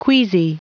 Prononciation du mot queasy en anglais (fichier audio)
Prononciation du mot : queasy